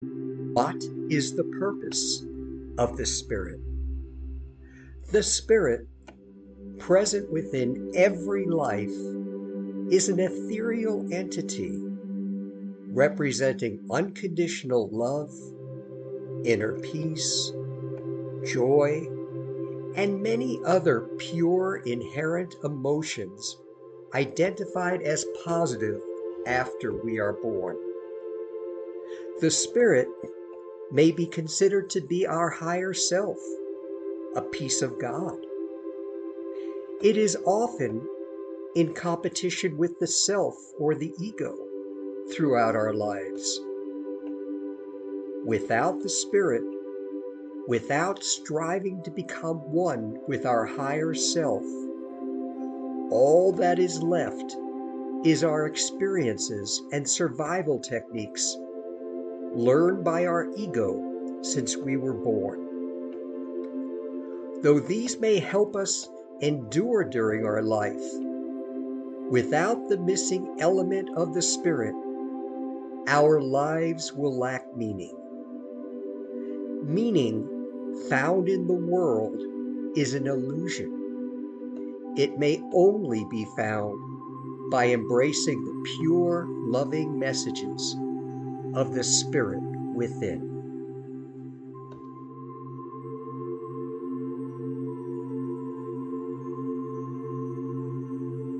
An audio Spiritual Reflection